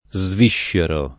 DOP: Dizionario di Ortografia e Pronunzia della lingua italiana